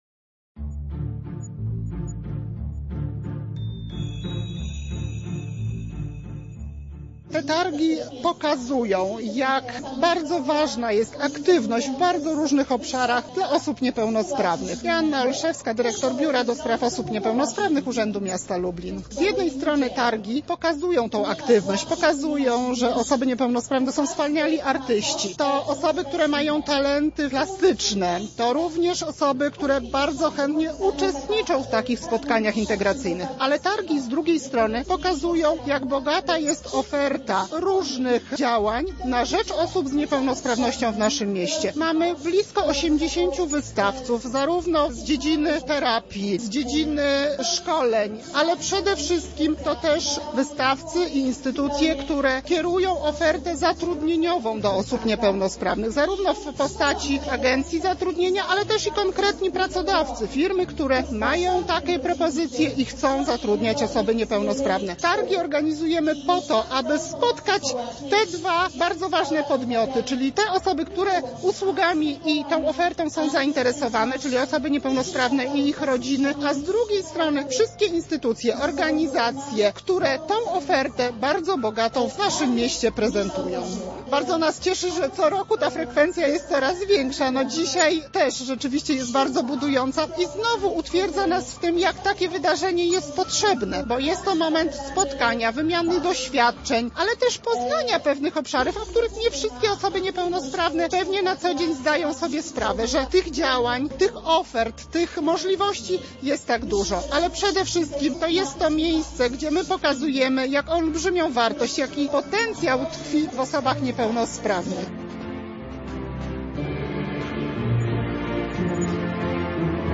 Za nami szósta edycja Lubelskich Targów Aktywności Osób Niepełnosprawnych.